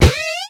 Cri de Moufouette dans Pokémon X et Y.